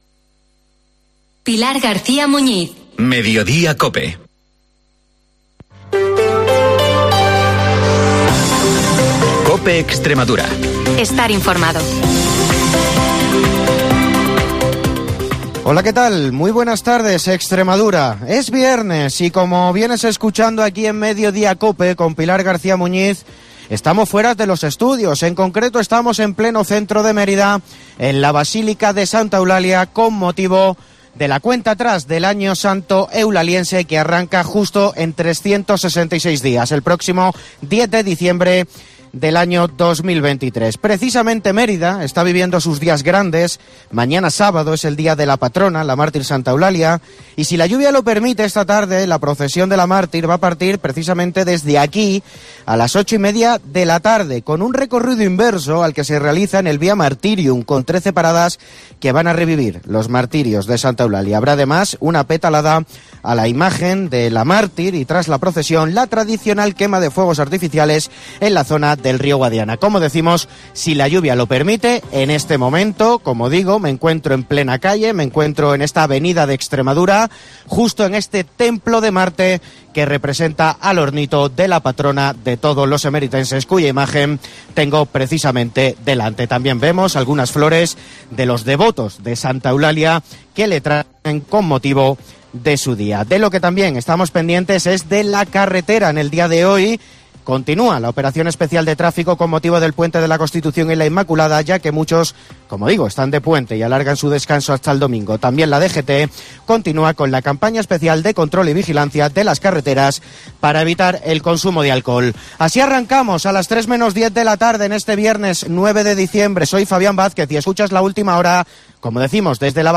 te cuenta la última hora de la región en Mediodía COPE